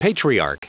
Prononciation du mot patriarch en anglais (fichier audio)
patriarch.wav